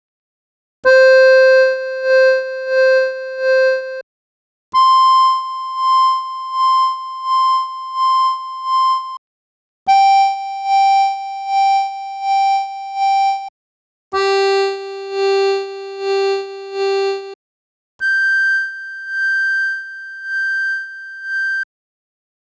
Vandaag stuitte ik op een zeer onprettig hoorbare storing bij het gebruik van de modulation wheel bij geluiden waarvan de modulation wheel het volume beïnvloed.
Wanneer je daar het modulation wheel gebruikt of wanneer je het volumepedaal gebruikt, hoor je een zeer onaangenaam bromachtig bijgeluid tijdens de volume overgang.
Een voorbeeld van de storing is te horen via deze link (opgenomen met de interne harddisk recorder):
PS: Luister met een koptelefoon om de storing nog beter te kunnen horen.
Wat je hoort zijn de overgangen tussen deze stappen, maar dat zou uiteraard niet hoorbaar mogen zijn.
Cassotto_modulationwheel.wav